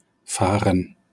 wymowa:
IPA/ˈfaːrən/ /fɛːʁt/ /fuɐ/ /gəˈfaːrən/